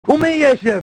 Worms speechbanks
jump2.wav